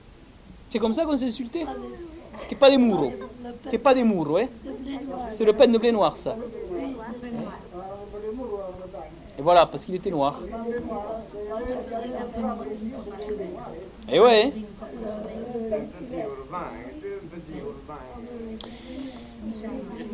Lieu : Castillon-en-Couserans
Genre : témoignage thématique